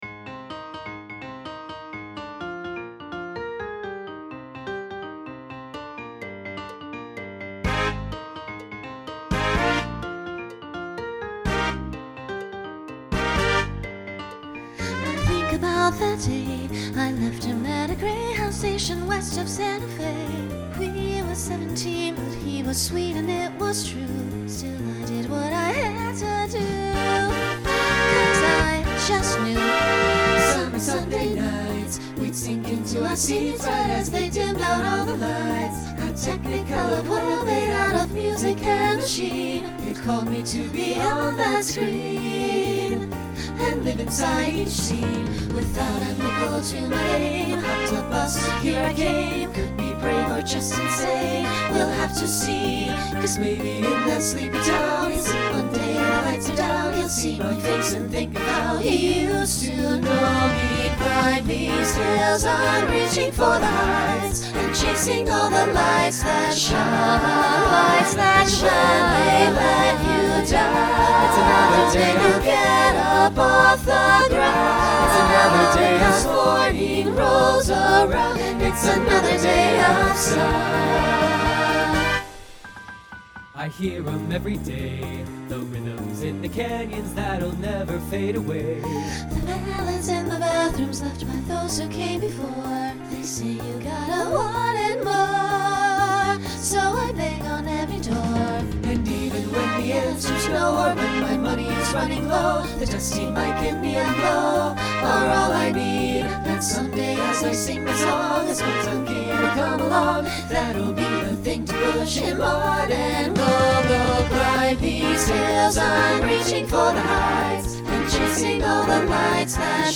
Broadway/Film , Swing/Jazz Instrumental combo
Solo Feature Voicing SATB